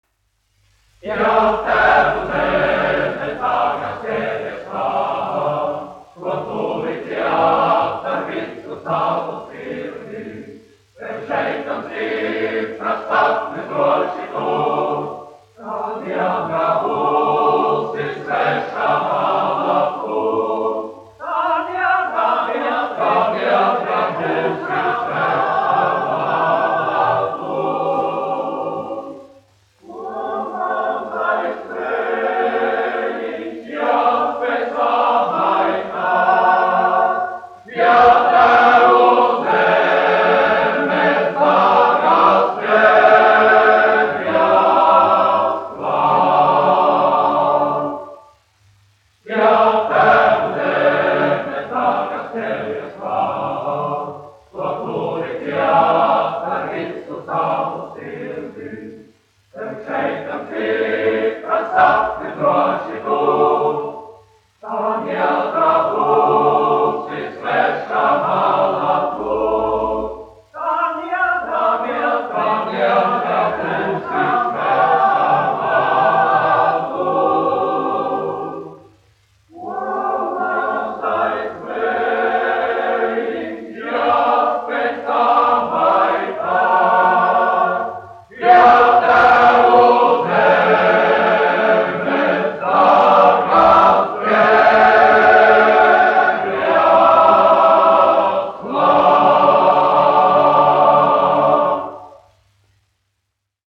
1 skpl. : analogs, 78 apgr/min, mono ; 25 cm
Kori (vīru)
Skaņuplate